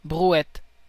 Ääntäminen
Synonyymit vinaigrette Ääntäminen Tuntematon aksentti: IPA: /bʁu.ɛt/also /bəʁu.ɛt/ IPA: /bʁu.ɛt/ Haettu sana löytyi näillä lähdekielillä: ranska Käännös Substantiivit 1. carretilla {f} Suku: f .